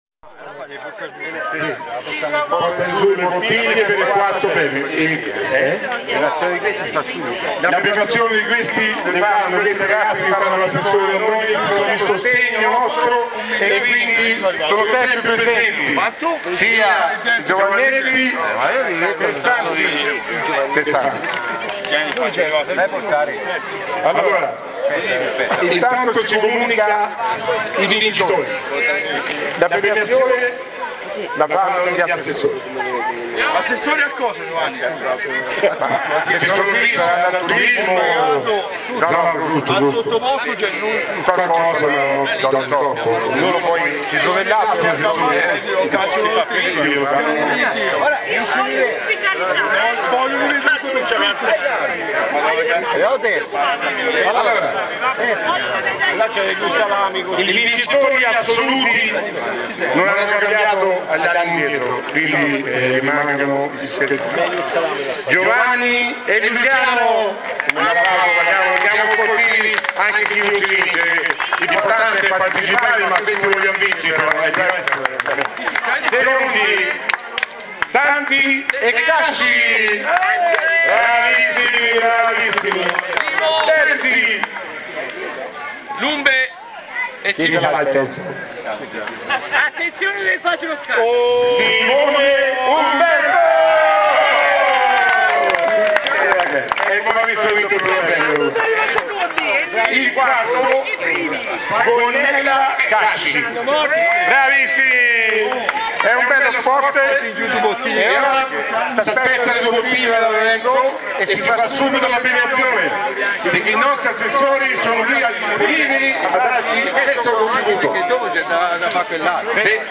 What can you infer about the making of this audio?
Click on the link below to hear the prize giving ceremony and the names of the winners of the log sawing competition la_mocchia_log_sawing_barga_9aug2009.mp3